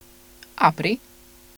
• apri_Q ./sounds/apri_Q : the recording of a word pronounced as a question
has two distinct syllables (A/PRI), the first being a bit shorter, and the second a bit trailing.
Auditorily, it's also obvious that their pitch differs greatly, with an expected final pitch rise in the question/source file.
Note also the two sounds don't start exactly at the same time in the file (the source sound as its onset around 600ms into the file; the target sound around 680ms), as often happens with naturalistic recordings.